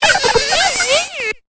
Cri d'Apitrini dans Pokémon Épée et Bouclier.